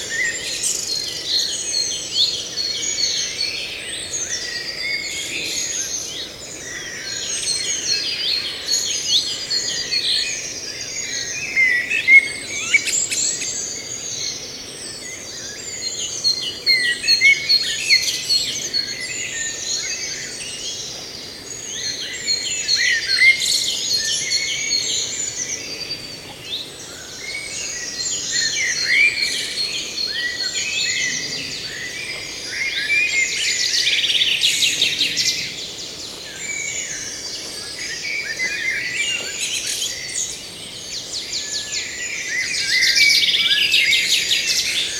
birds screaming loop.ogg